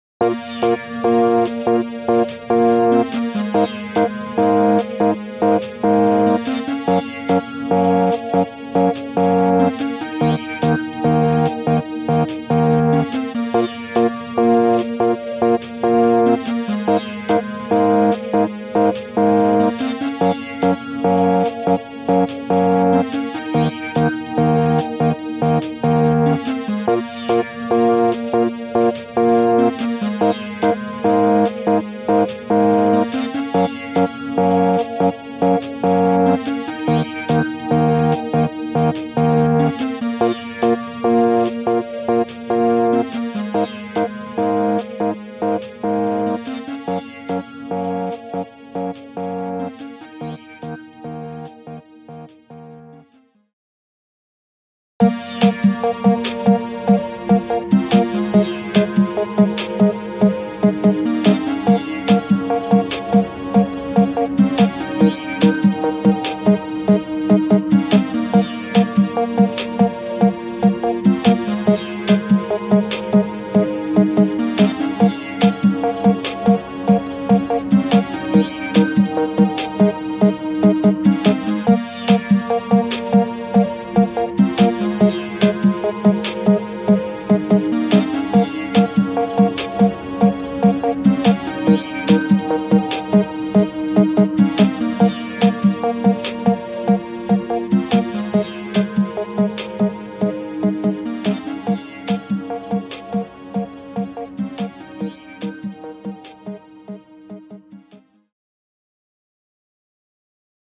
Retro track for transitions & stingers.